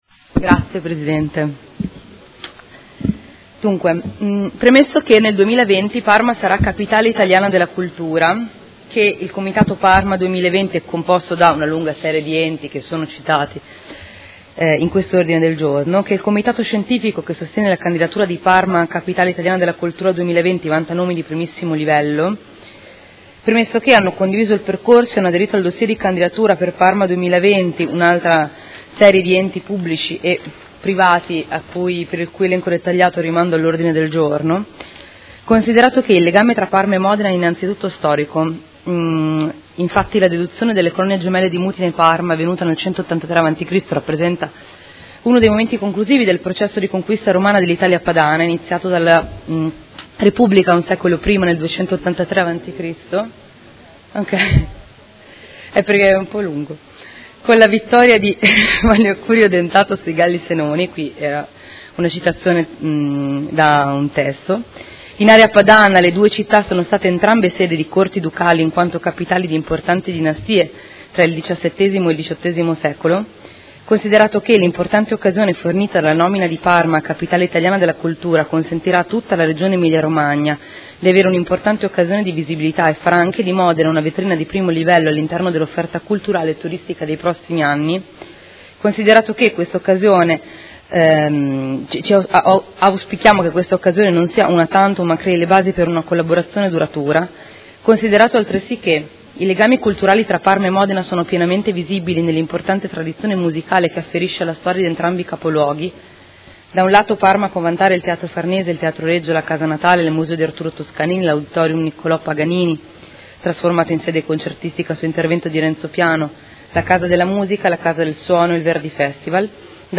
Seduta del 13/12/2018. Ordine del Giorno presentato dai Consiglieri Di Padova, Lenzini, Liotti, Forghieri, De Lillo e Arletti (PD) avente per oggetto: Parma capitale italiana della cultura 2020, quale ruolo per Modena?